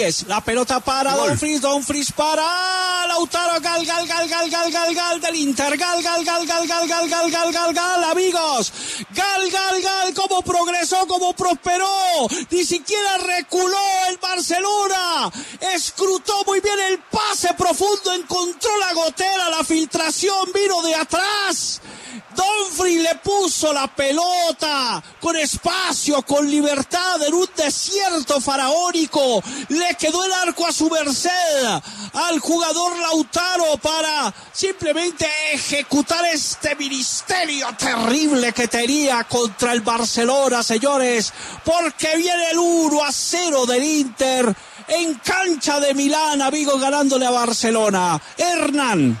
Así fue la narración de Martín de Francisco del gol del Inter: